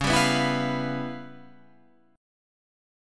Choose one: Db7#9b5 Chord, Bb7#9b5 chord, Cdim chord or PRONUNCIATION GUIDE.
Db7#9b5 Chord